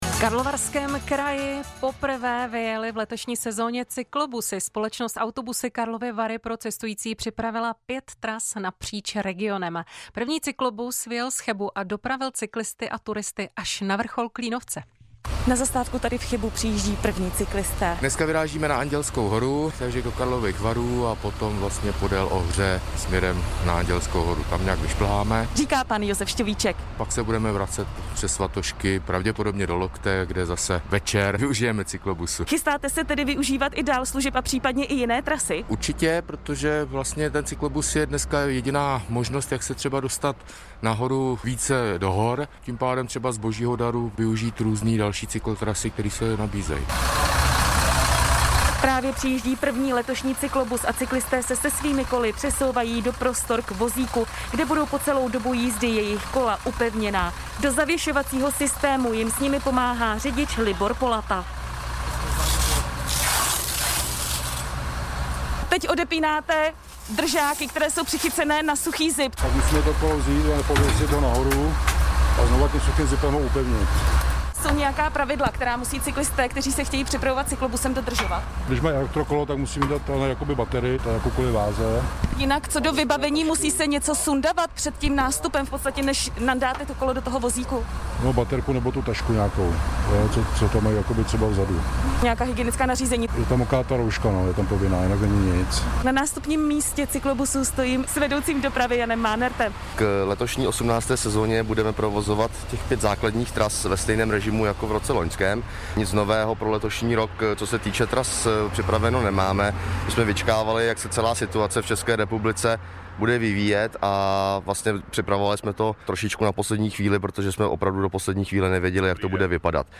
Rozhovor v Českém Rozhlase
rozhovor-cesky-rozhlas.mp3